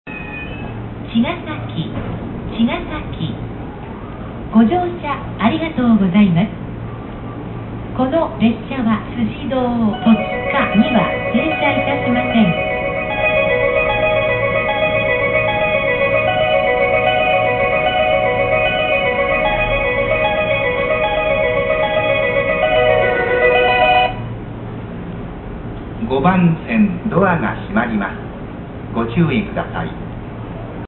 駅名連呼「茅ヶ崎駅」
これは、「快速アクティ」停車時に流れたもので連呼後に通過駅があることを案内しています。